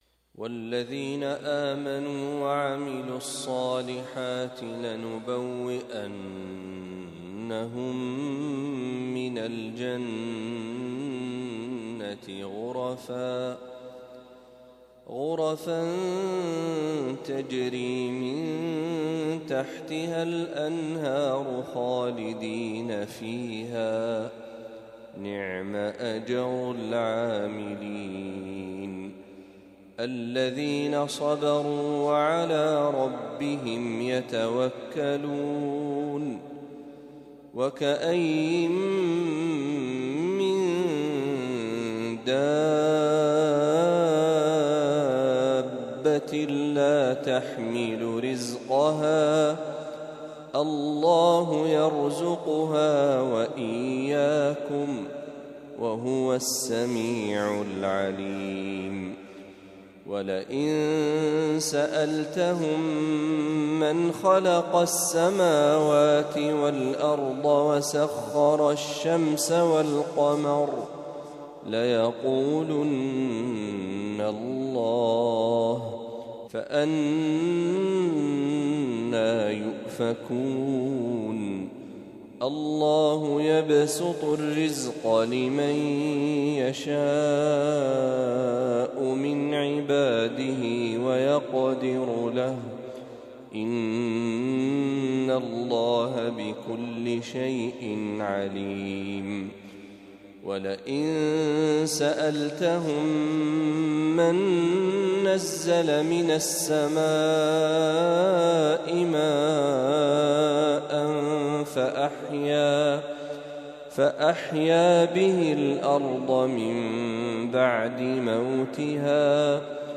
تلاوة من سورة العنكبوت | فجر الخميس ١٢ محرم ١٤٤٦هـ